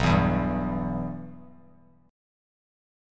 Bsus4#5 chord